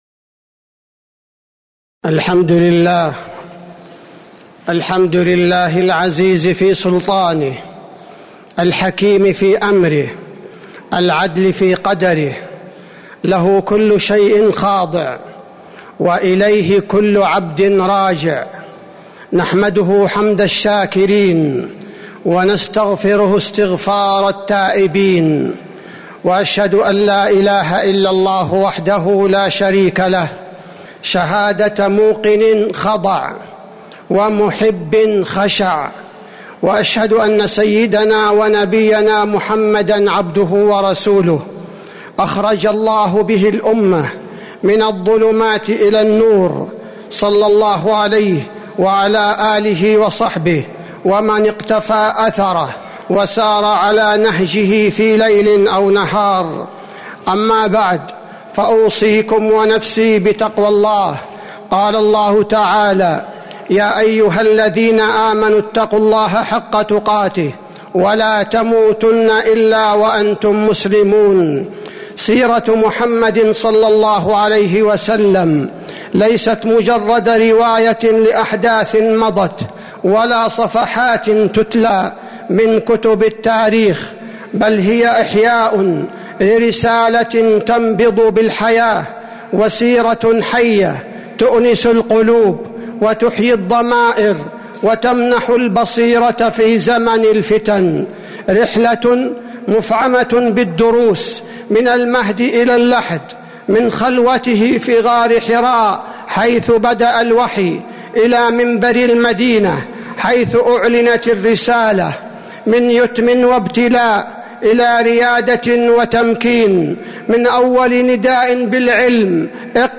تاريخ النشر ١١ ذو القعدة ١٤٤٦ هـ المكان: المسجد النبوي الشيخ: فضيلة الشيخ عبدالباري الثبيتي فضيلة الشيخ عبدالباري الثبيتي قبس من عبق السيرة النبوية الشريفة